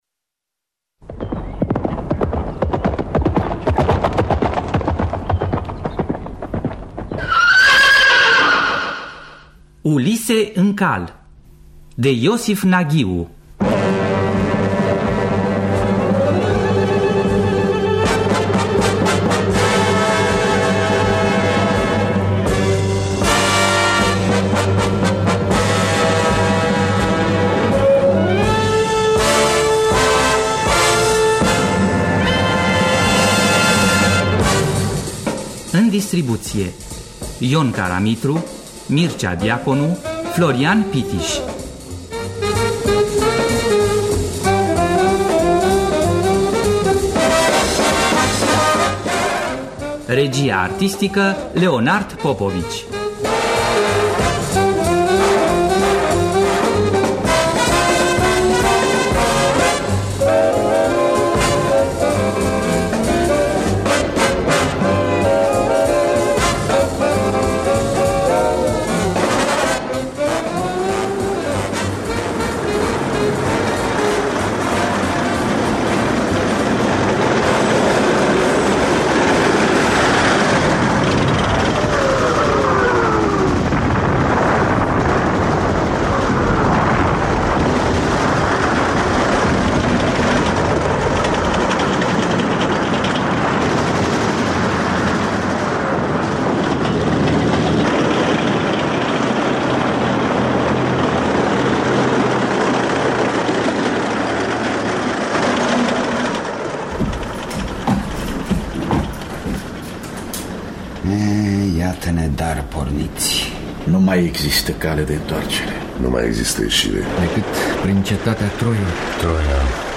Ulise în cal de Iosif Naghiu – Teatru Radiofonic Online